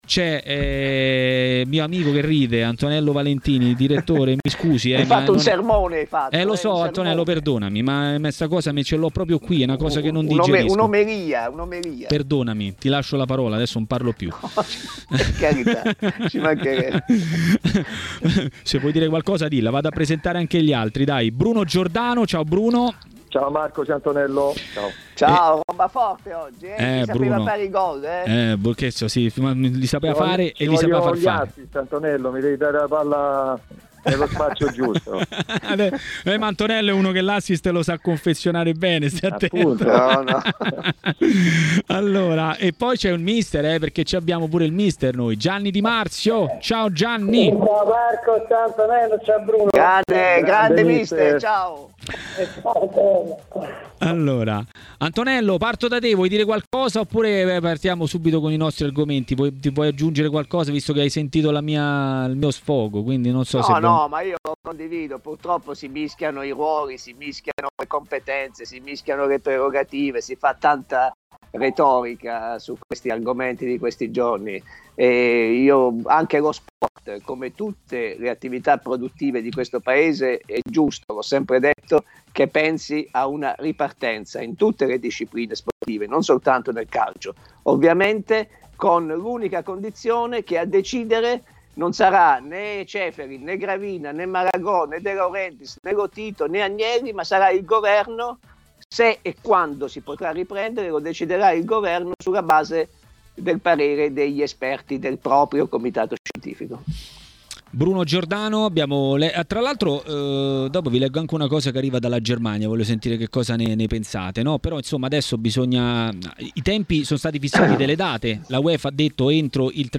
ha parlato della ripresa del campionato (e non solo) in diretta a TMW Radio, durante Maracanà.